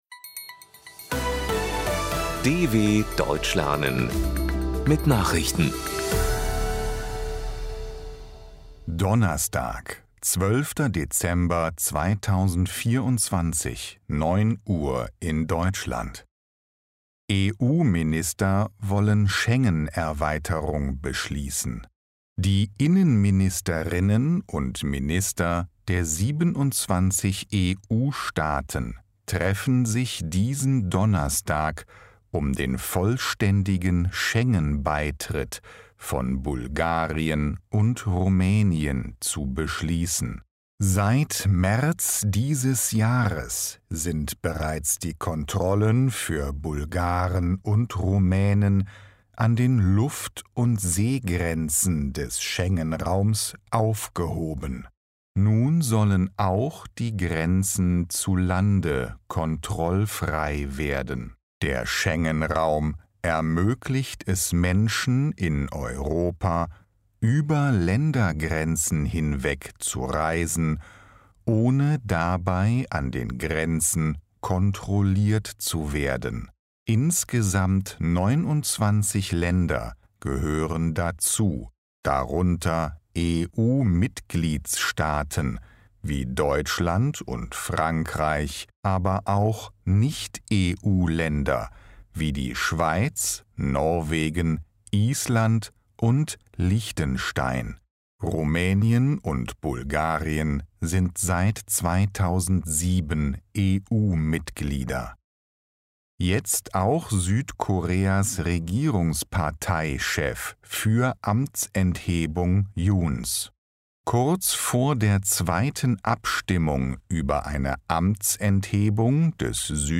Die Langsam gesprochenen Nachrichten der Deutschen Welle bieten von Montag bis Samstag aktuelle Tagesnachrichten aus aller Welt. Das langsam und verständlich gesprochene Audio trainiert das Hörverstehen.